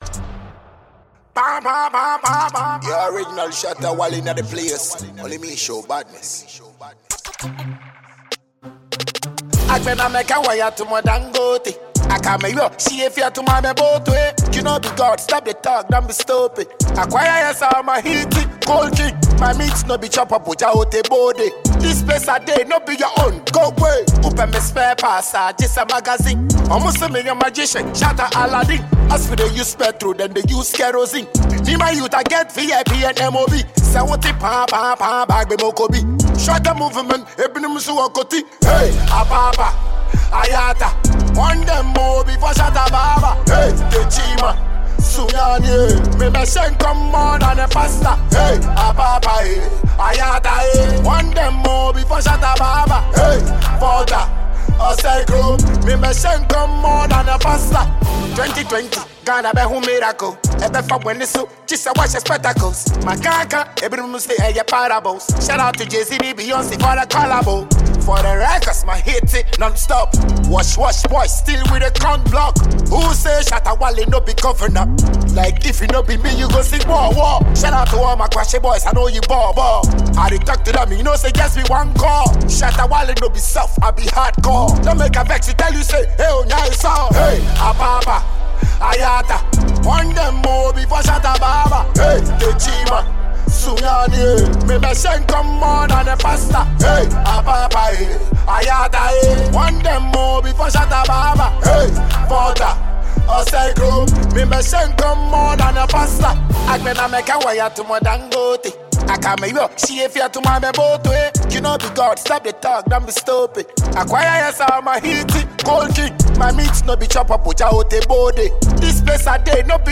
afro dancehall